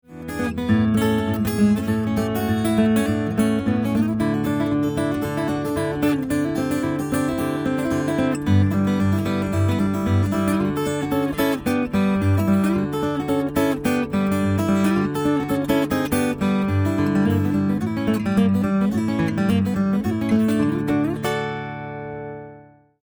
one guitar, one voice, no overdubs.